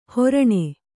♪ horaṇe